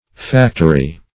Factory \Fac"to*ry\, n.; pl.